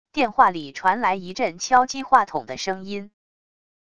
电话里传来一阵敲击话筒的声音wav音频